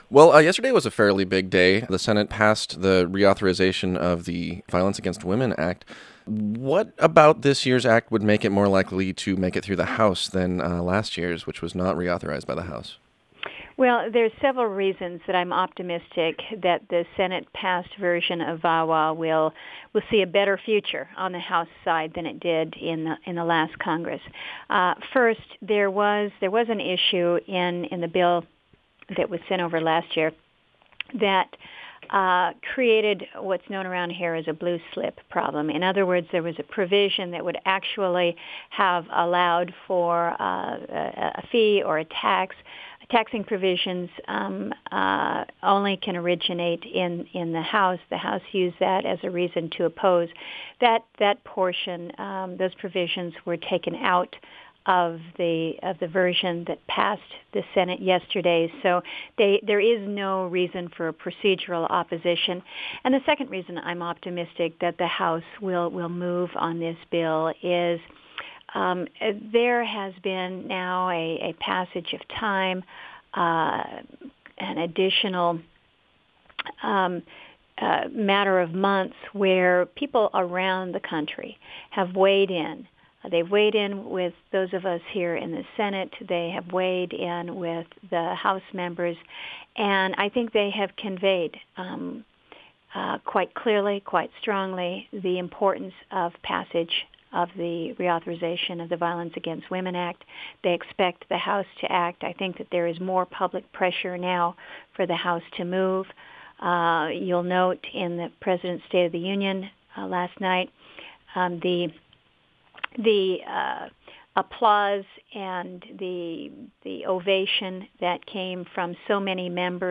Senator Murkowski: Full KTNA Interview